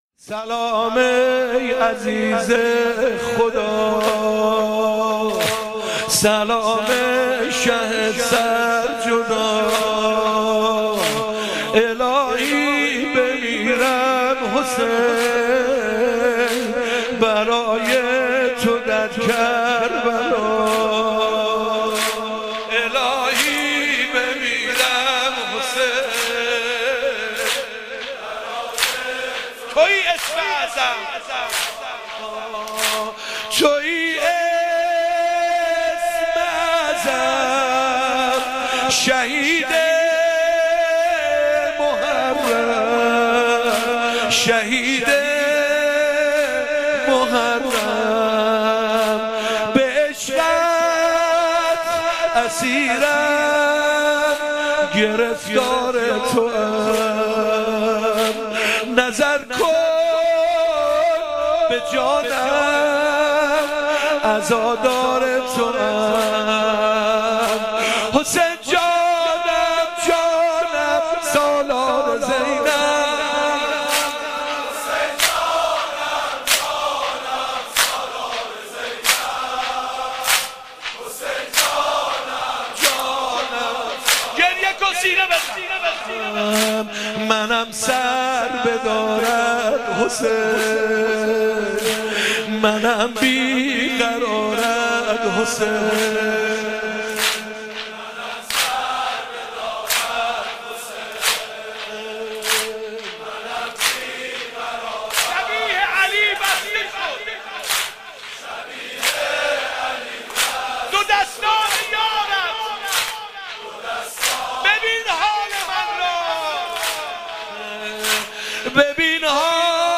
نوحه - سلام ای عزیز خدا